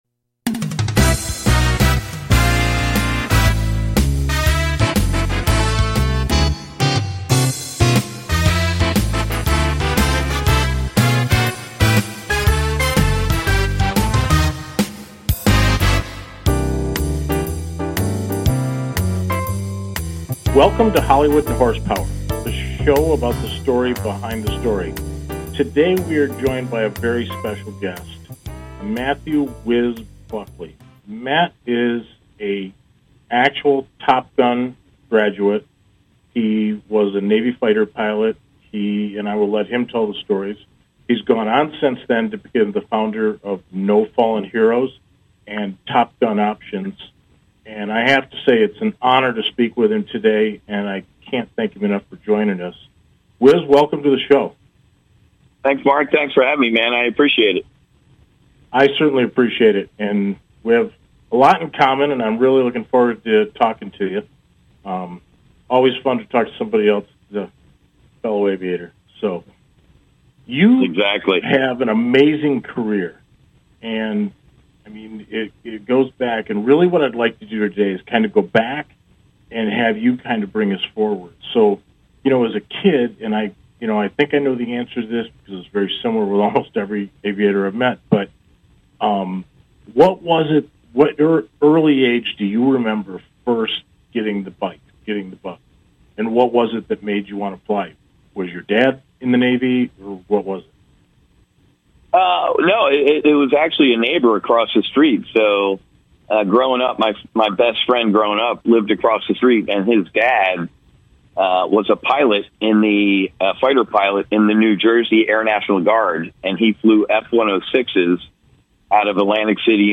It is where SNL meets The Tonight Show; a perfect mix of talk and comedy.